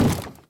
MinecraftConsoles / Minecraft.Client / Windows64Media / Sound / Minecraft / mob / zombie / wood3.ogg
wood3.ogg